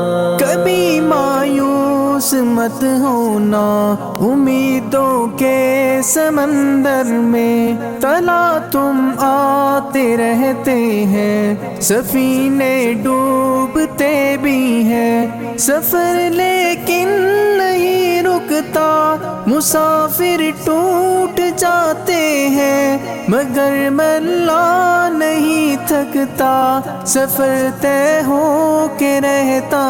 Naat Ringtones